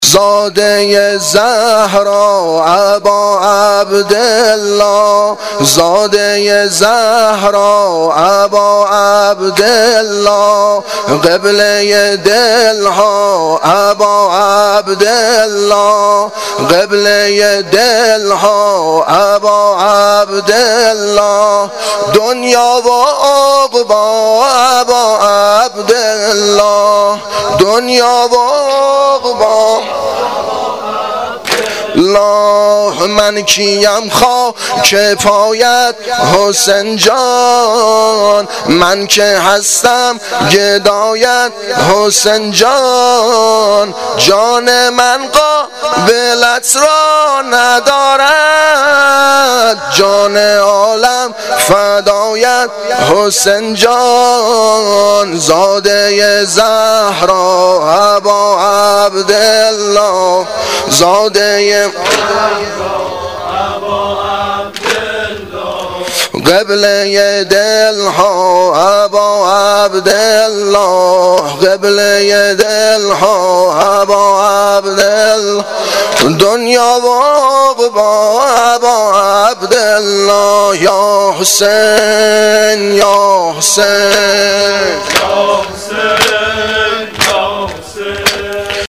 واحد شب پنجم محرم الحرام 1396